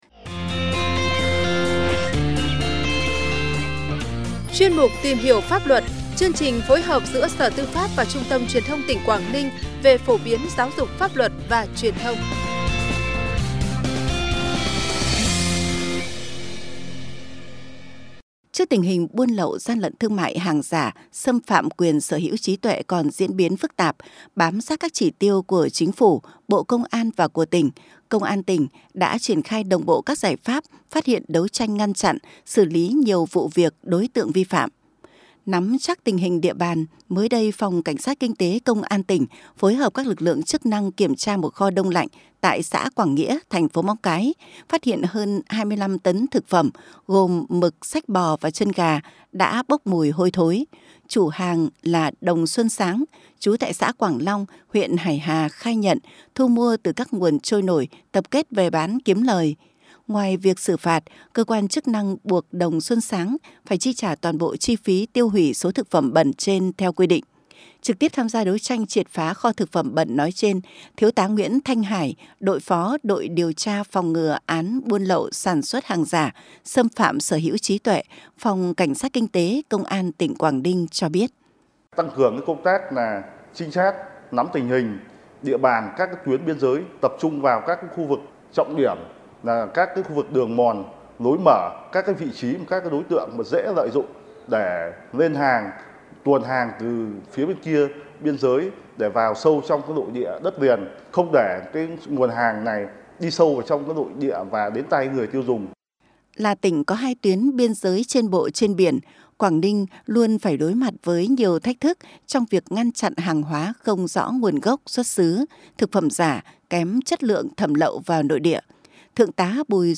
(Phóng sự phát thanh) Tăng cường đấu tranh hiệu quả với gian lận thương mại, thực phẩm bẩn